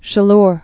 (shə-lr, -lûr)